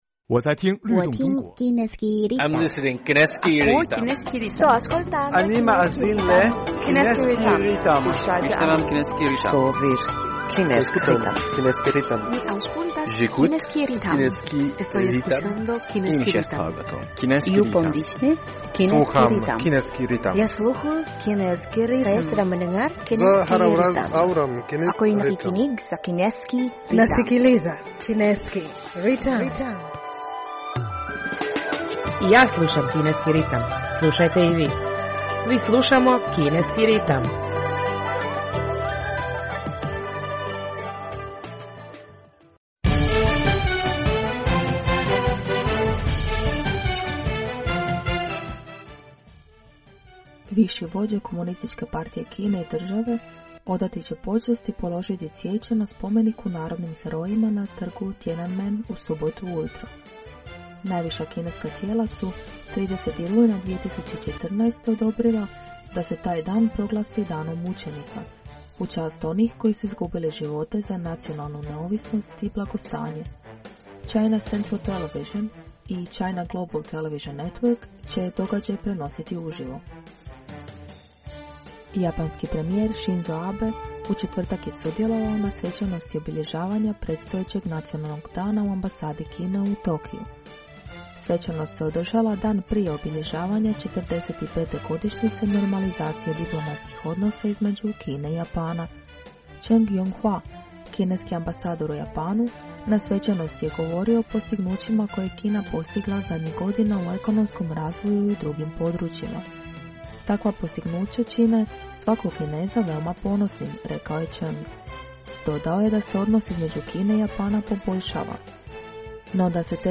Slušate program na hrvatskom jeziku Kineskog radio Internacionala!